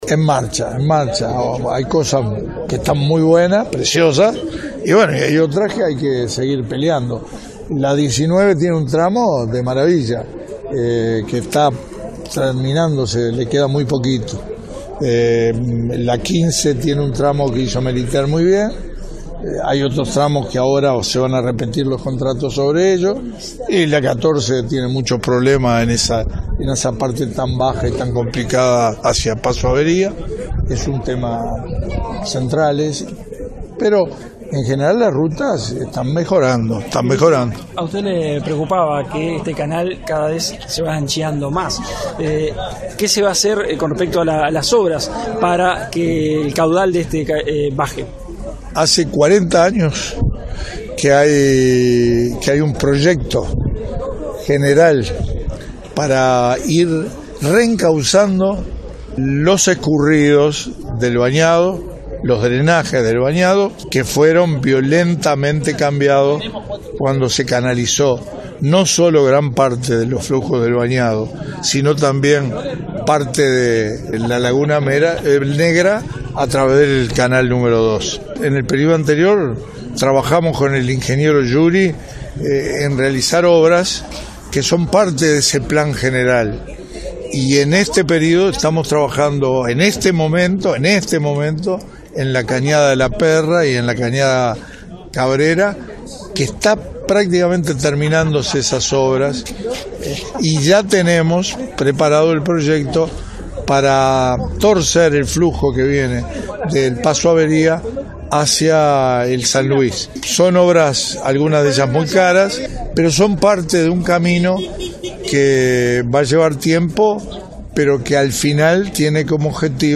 “Las obras viales en el departamento de Rocha están en marcha y las rutas están mejorando”, dijo el ministro de Transporte, Víctor Rossi, tras el recorrido por las rutas 19, 15 y 14. Explicó que “buena parte” de los problemas en las rutas se deben al tránsito de camiones de carga, “actividad que en 2005 no pasaba”.